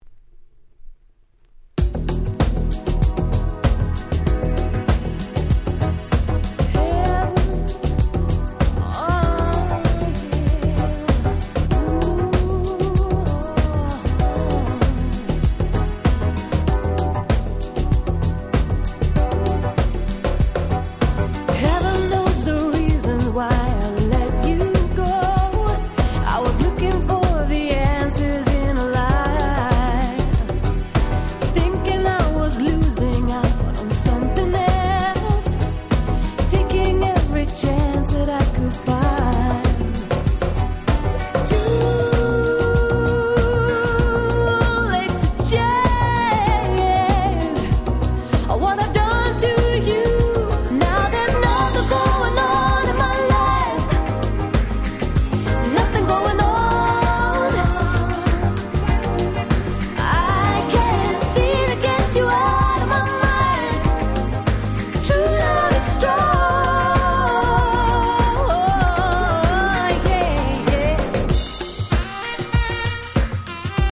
92' Very Nice UK R&B / UK Street Soul Album !!
もう教科書通りの爽やかで素敵なUK Soulしか入っておりません。